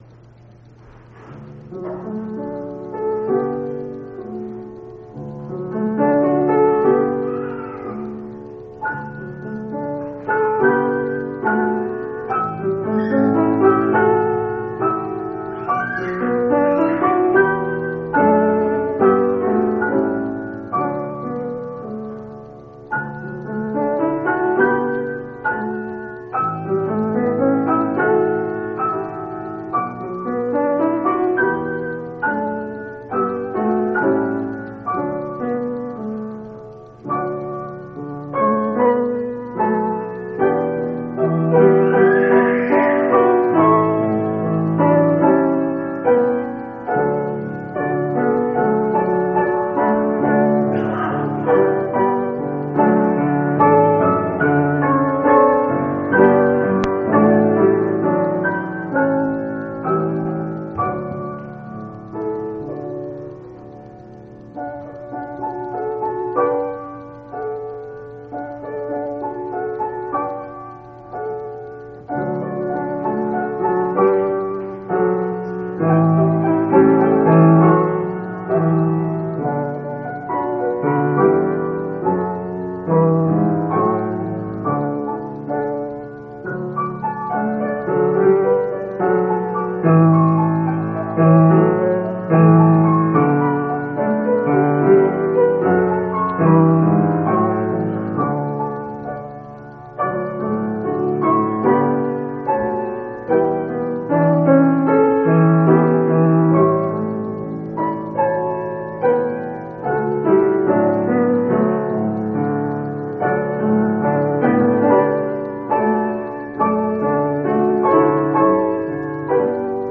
Event: General Church Conference